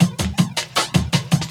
12 LOOP14 -R.wav